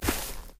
255081e1ee Divergent / mods / Soundscape Overhaul / gamedata / sounds / material / human / step / tmp_default2.ogg 19 KiB (Stored with Git LFS) Raw History